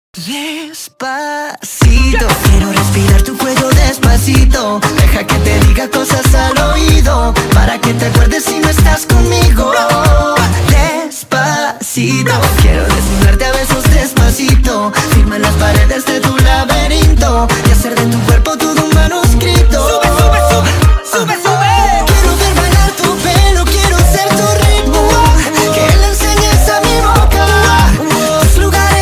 ژانر: بی کلام
اهنگ زنگ خیلی شاد